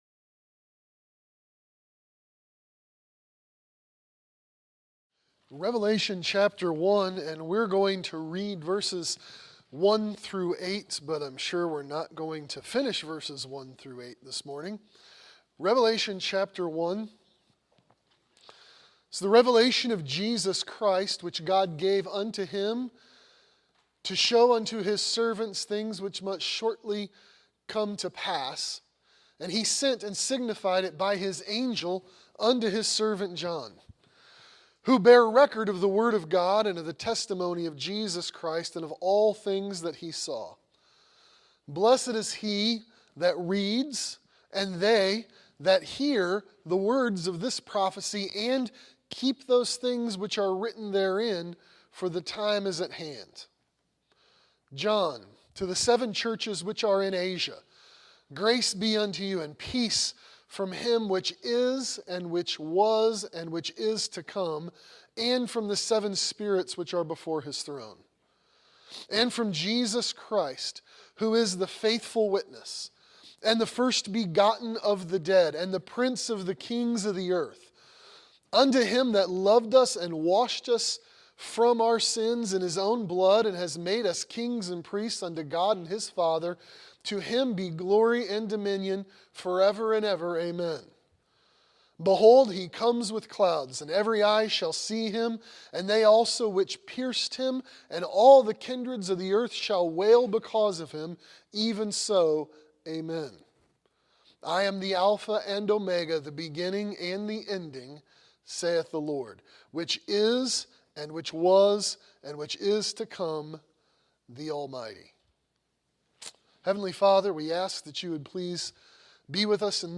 The Relevance of Revelation | SermonAudio Broadcaster is Live View the Live Stream Share this sermon Disabled by adblocker Copy URL Copied!